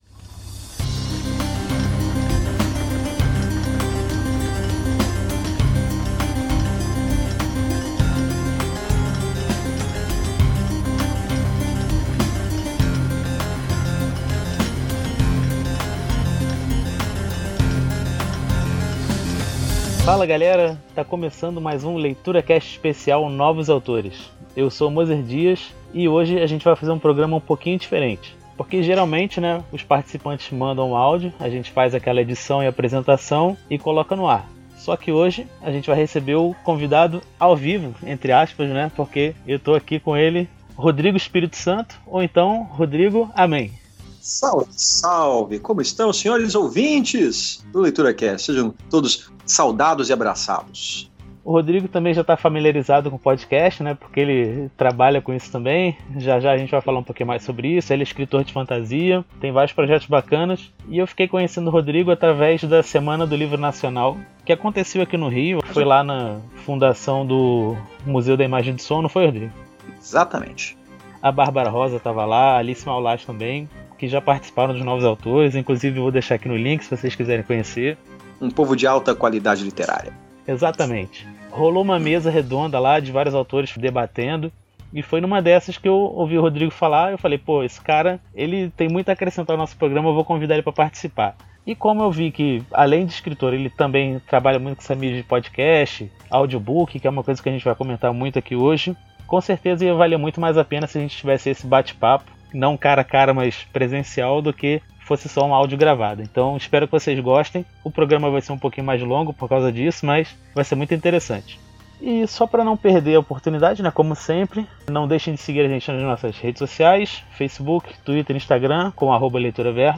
Novos Autores 40: entrevista